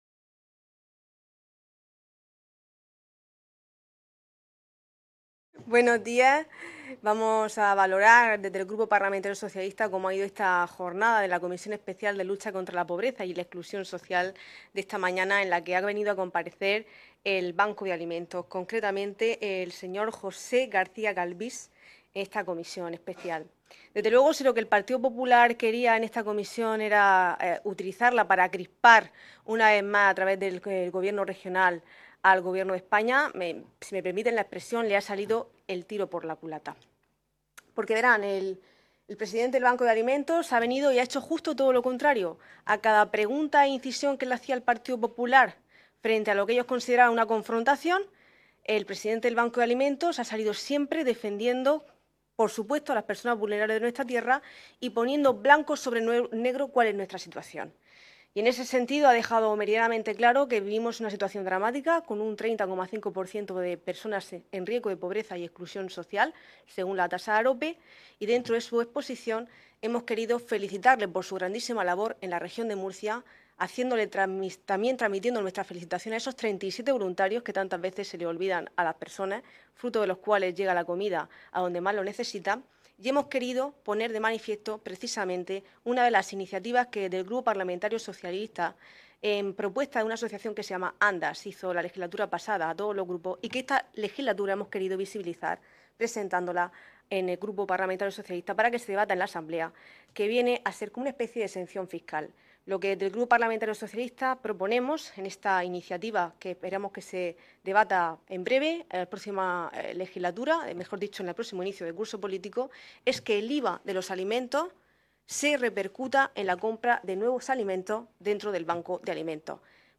Ruedas de prensa tras la Comisión Especial de Estudio de Lucha contra la Pobreza y la Exclusión Social en la Región de Murcia